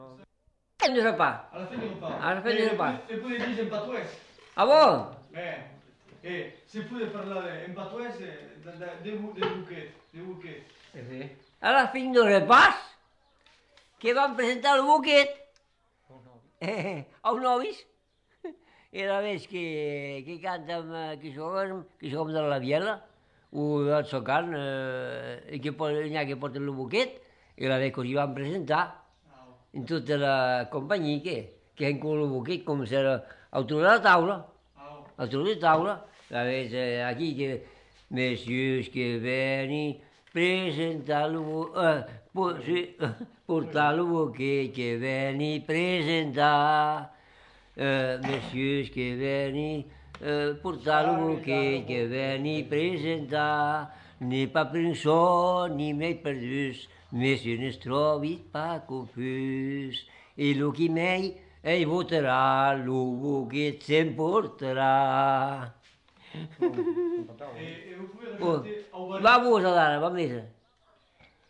Aire culturelle : Gabardan
Lieu : Vielle-Soubiran
Genre : chant
Effectif : 1
Type de voix : voix d'homme
Production du son : chanté
Notes consultables : Commentaires sur la coutume du bouquet en début de séquence.